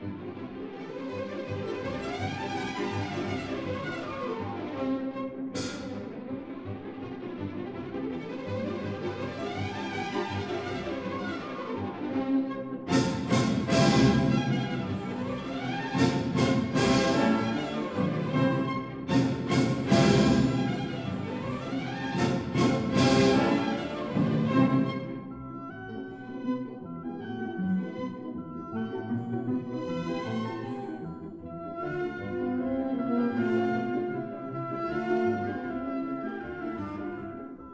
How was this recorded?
1961 Stereo Recording